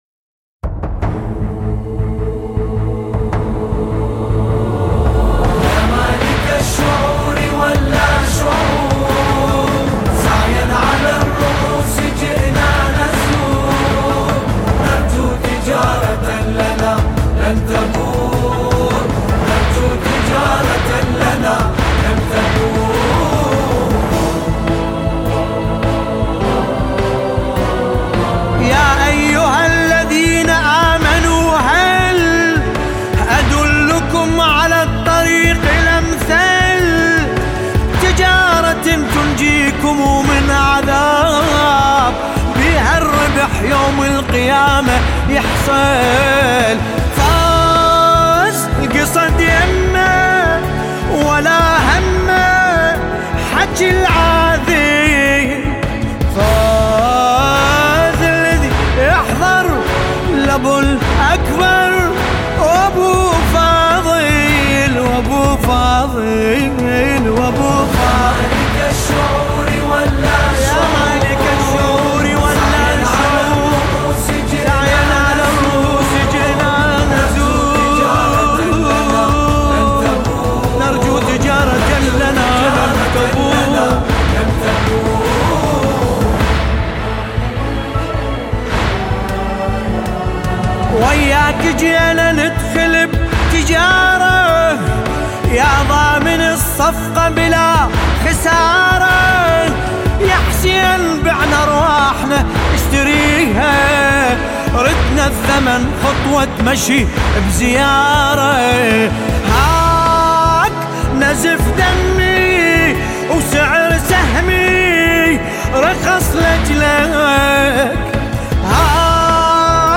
1439 سینه زنی